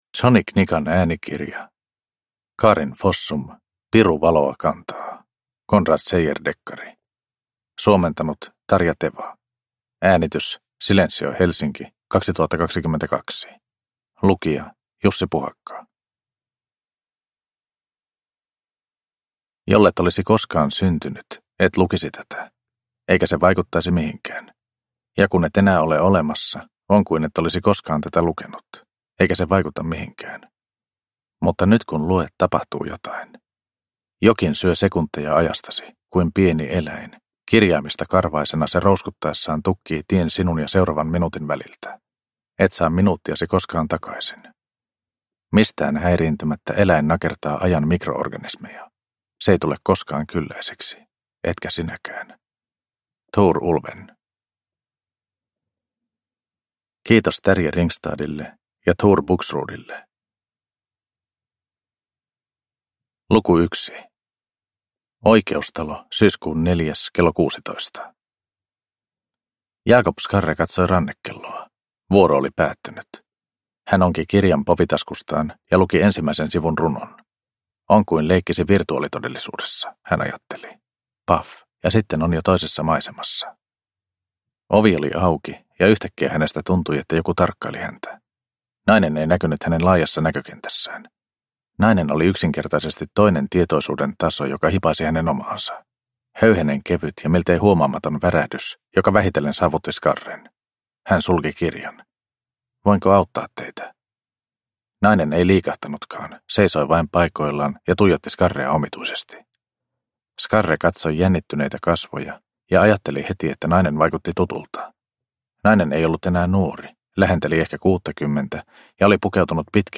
Piru valoa kantaa – Ljudbok – Laddas ner